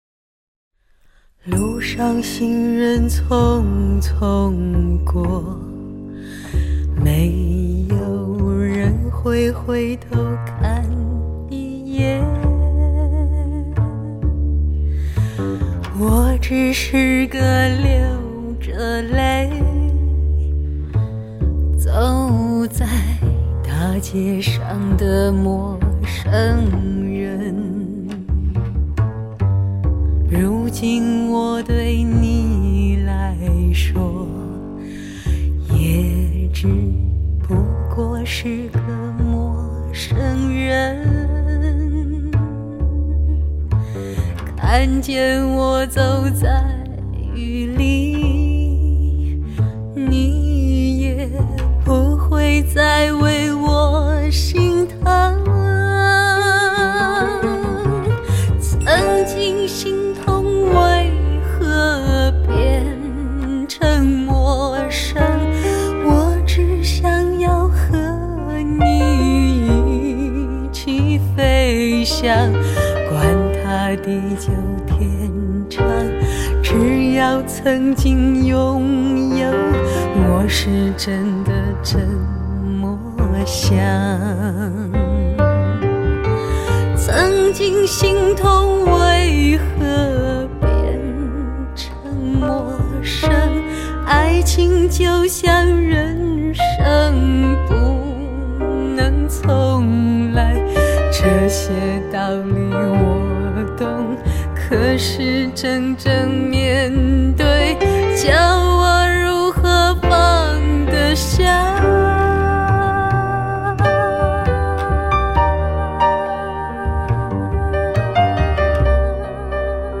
这群来自四面八方的乐手凑在一起演奏，配合得默契自然，所有歌曲的音乐部分以现场（Live）的方式收录。
这张经过24比特HDCD技术处理的唱片，肯定是今年上市的国产录音中优秀的女声天碟之一。